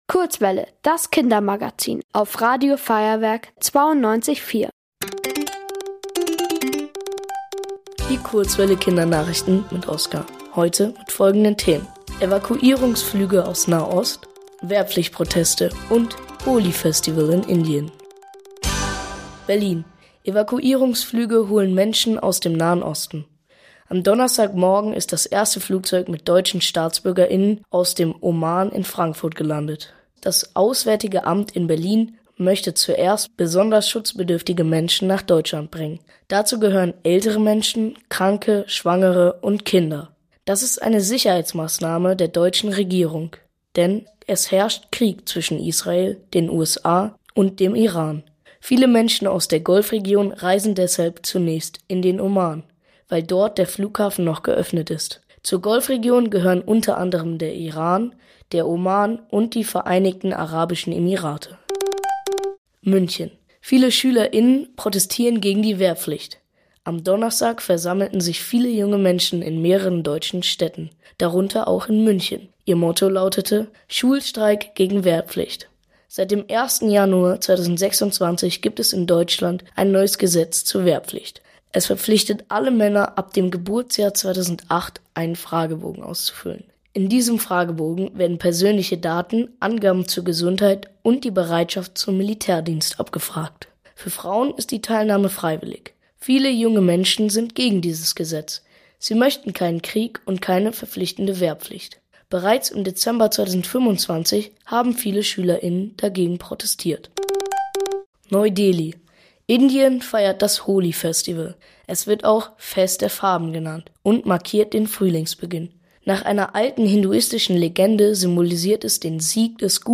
Die Kurzwelle Kindernachrichten vom 07.03.2026